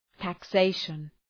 Προφορά
{tæk’seıʃən}